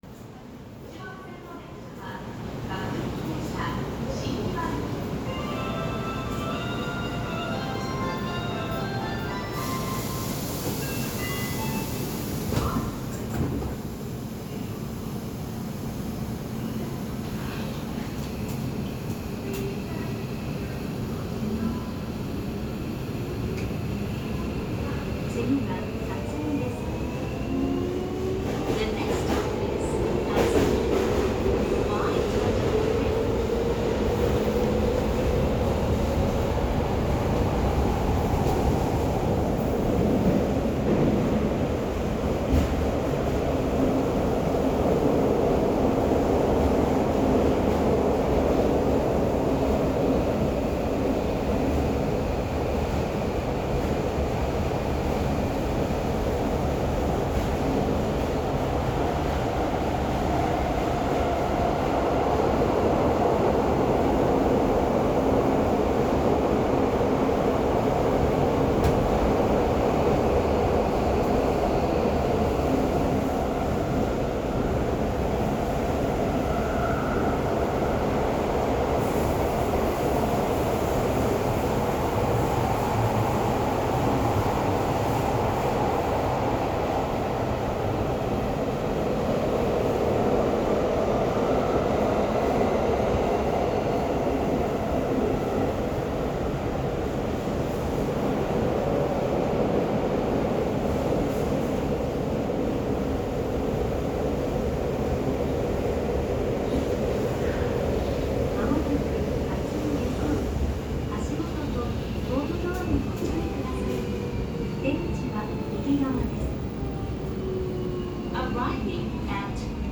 ・40000系走行音
【有楽町線】豊洲→辰巳
東芝PMSMを採用していますが、モーター車に乗っていてこの静けさ。かなりの静粛性を誇っていることがうかがえます。外で音を聞いてみても、最初の転調以降のモーター音が殆ど聞こえません。
toyosu.mp3